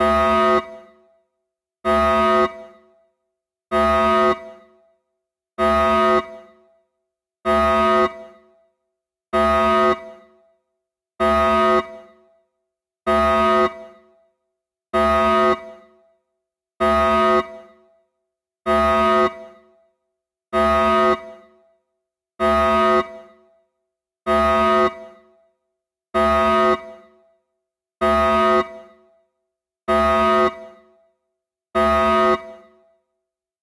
alarm.ogg